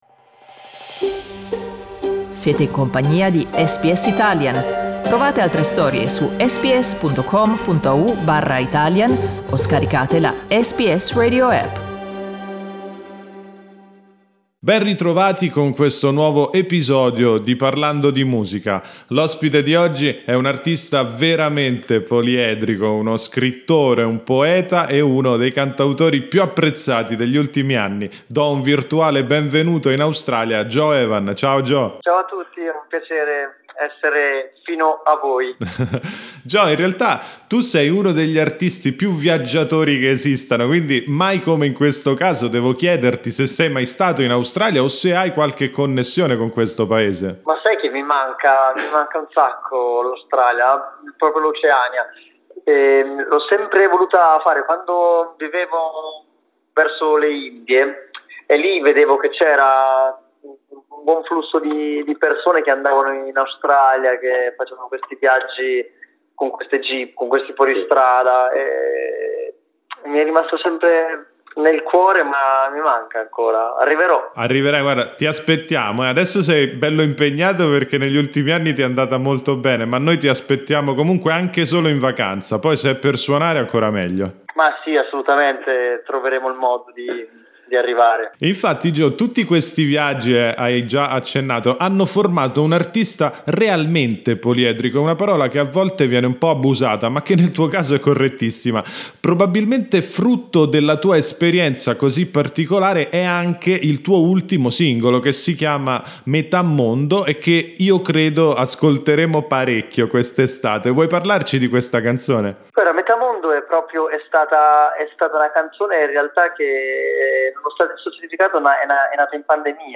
Dopo il successo di “Arnica”, presentata al Festival di Sanremo 2021, Gio Evan torna con un nuovo singolo e si racconta ai microfoni di SBS Italian.